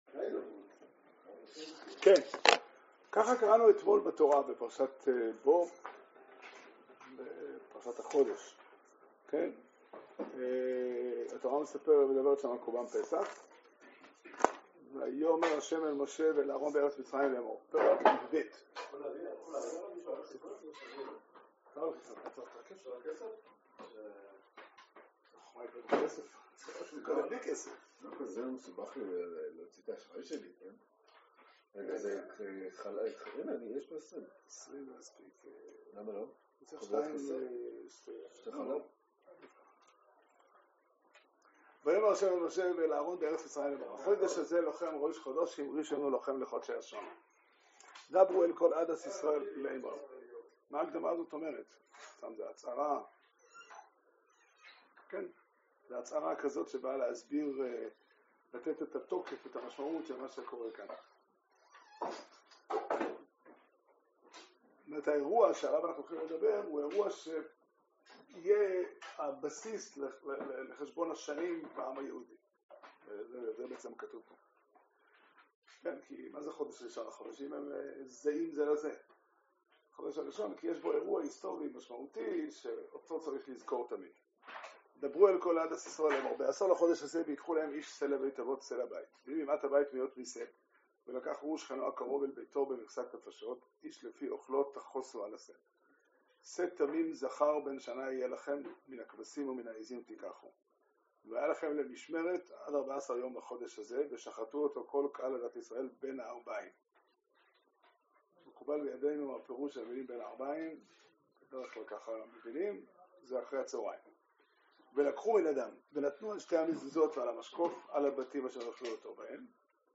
שיעור שנמסר בבית המדרש פתחי עולם בתאריך כ״ה באדר תשפ״ג